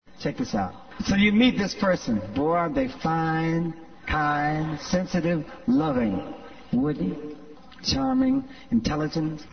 Tags: Travel Asyndeton Figure of Speech Brachylogia Speeches